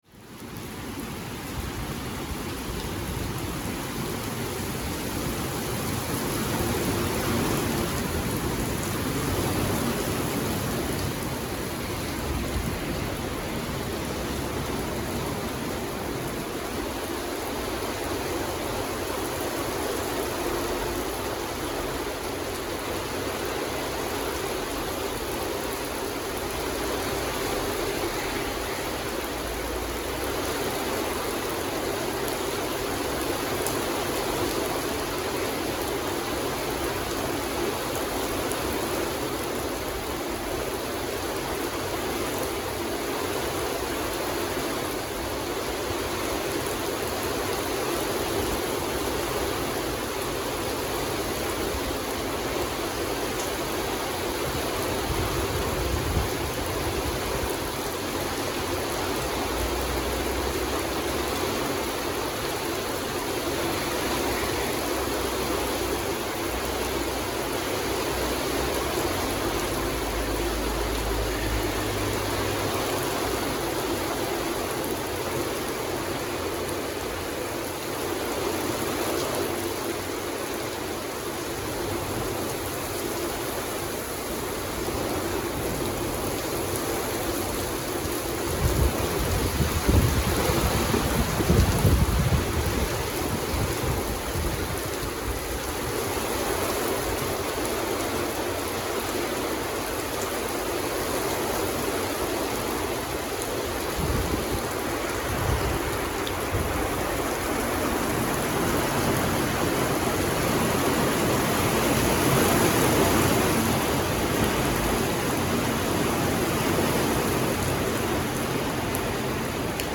Шум летнего ливня